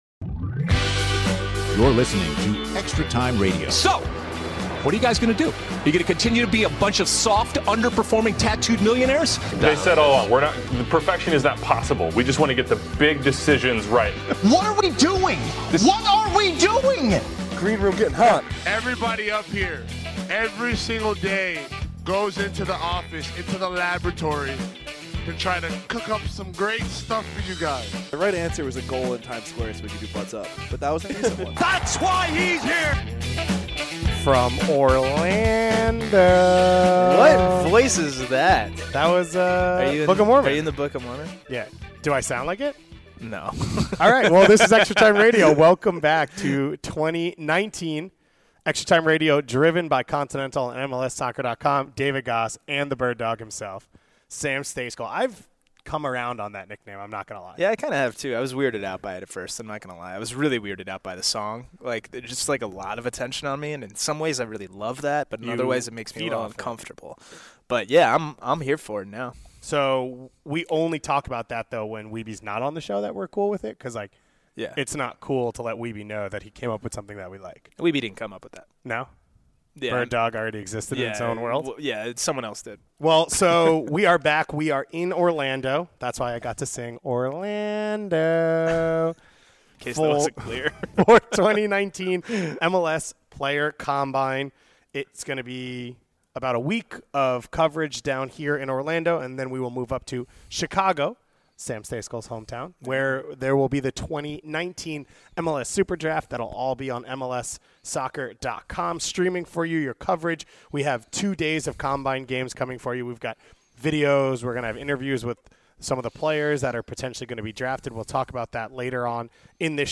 The guys give their takes then tackle listener takes in the mailbag.